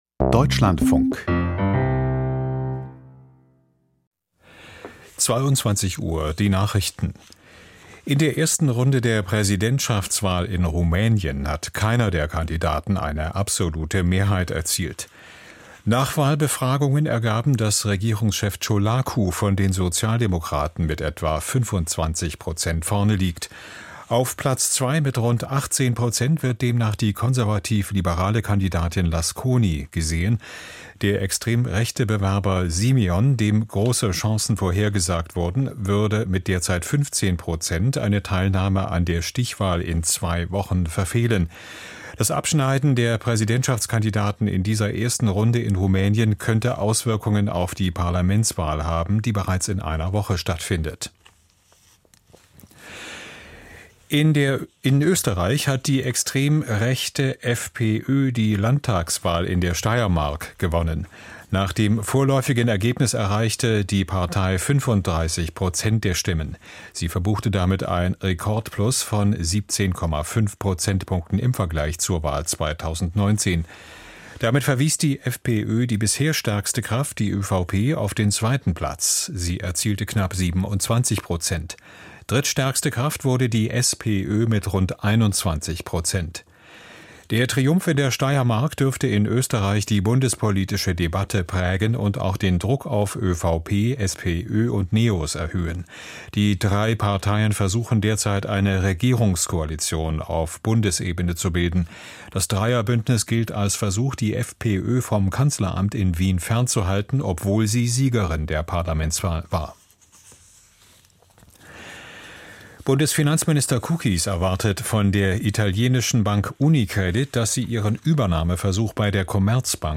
Die Nachrichten ➕ Abone ol ➕ Abonelik ✔ Abone olundu ✔ Abone olundu Çal Çalıyor Paylaş Tümünü oynan(ma)dı işaretle ...